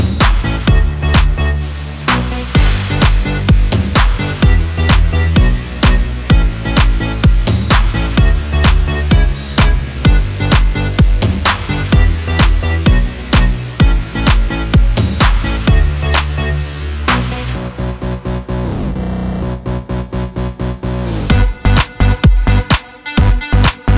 No Backing Vocals Dance 3:20 Buy £1.50